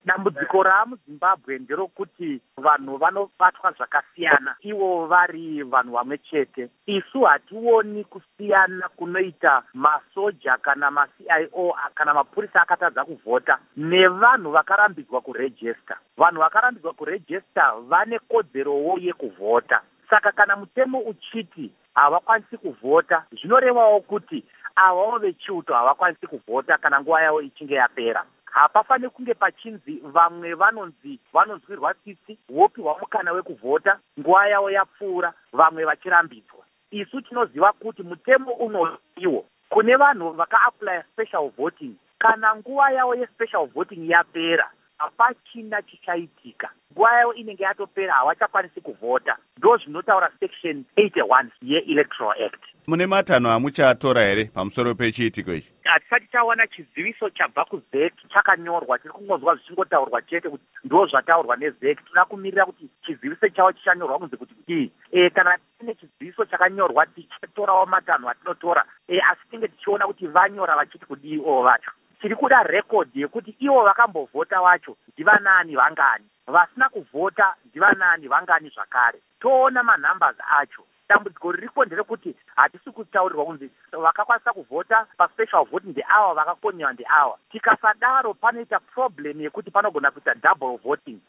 Hurukuro naVaDouglas Mwonzora